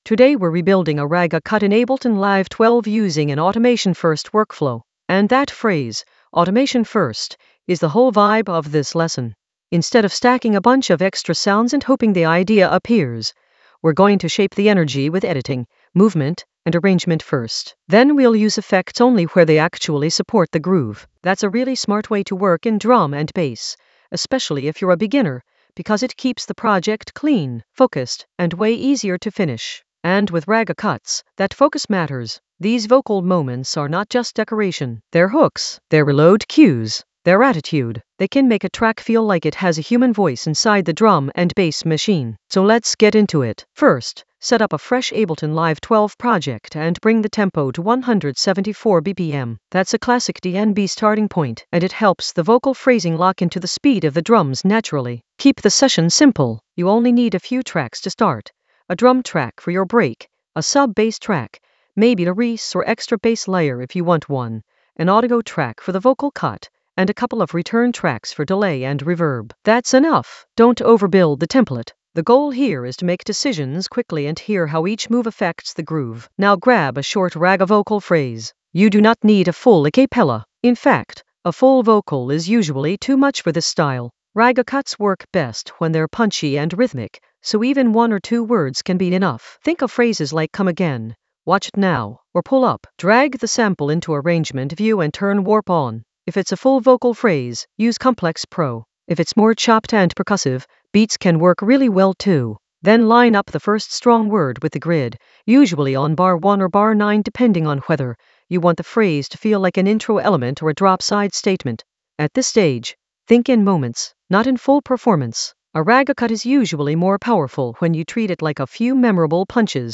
An AI-generated beginner Ableton lesson focused on Ragga cut in Ableton Live 12: rebuild it with automation-first workflow in the Ragga Elements area of drum and bass production.
Narrated lesson audio
The voice track includes the tutorial plus extra teacher commentary.